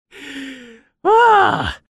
SFX叹气音效下载
这是一个免费素材，欢迎下载；音效素材为叹气， 格式为 mp3，大小1 MB，源文件无水印干扰，欢迎使用国外素材网。